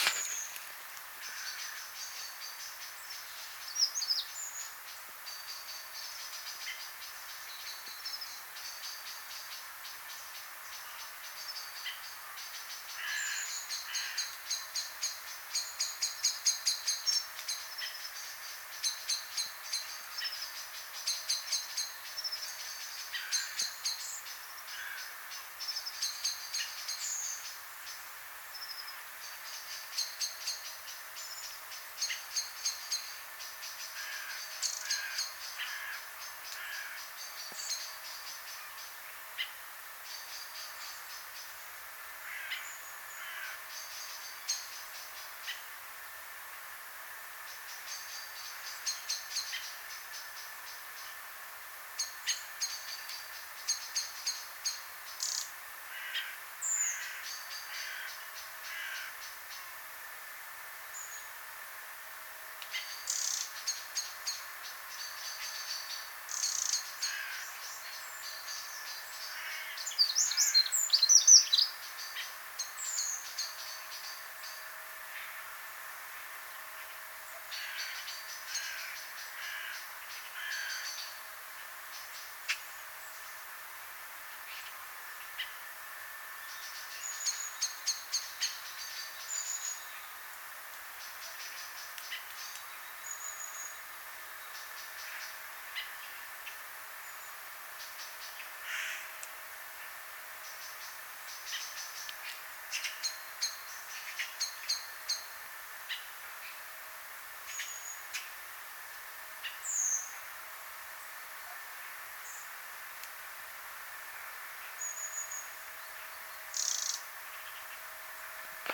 Data resource Xeno-canto - Soundscapes from around the world